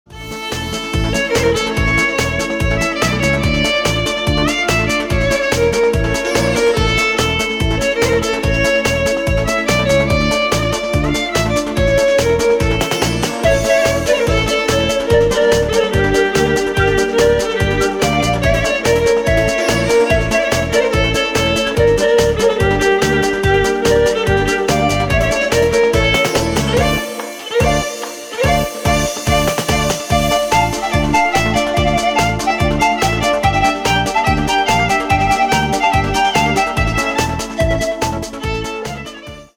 • Качество: 256, Stereo